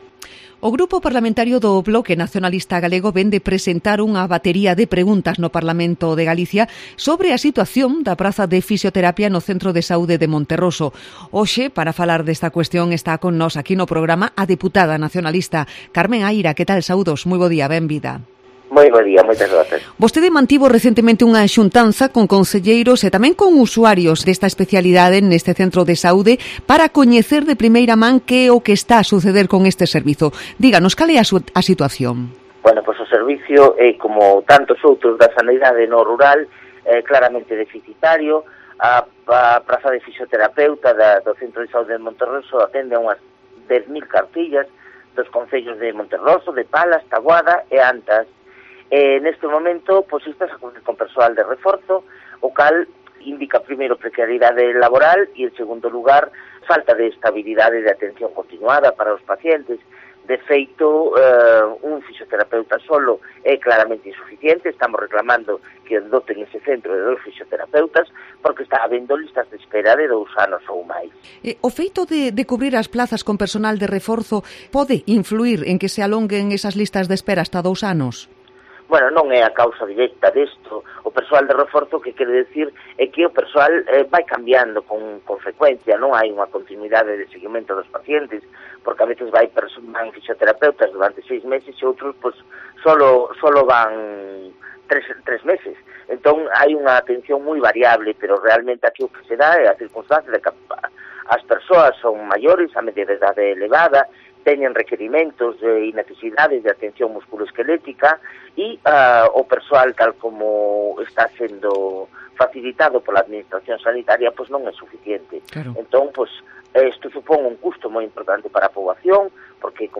Entrevista a Carme Aira, diputada del BNG en el Parlamento de Galicia